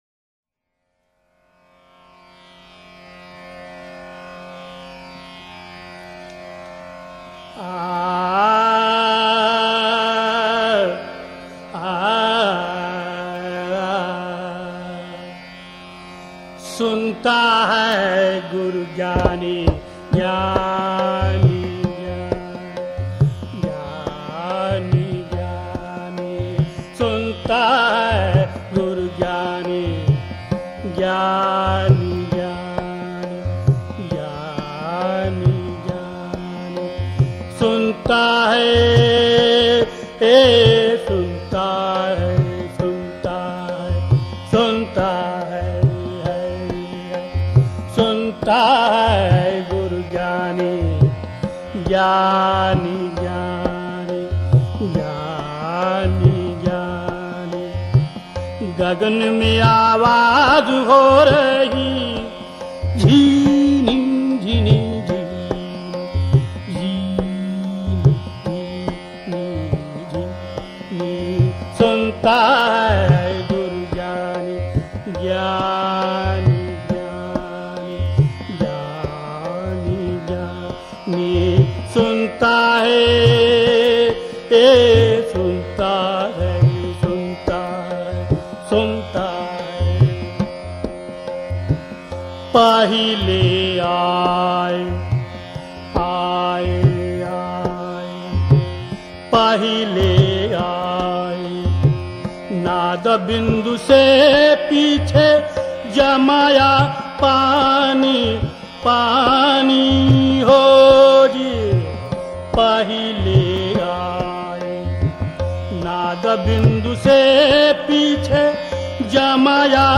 I was enjoying Marathi abhangs written by the 15th century saint Eknath. They are devotional songs expressing love and devotion to Vitthala, another name of Bhagwan Vishnu.